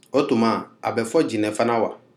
This is a dialogue of people speaking Nko as their primary language.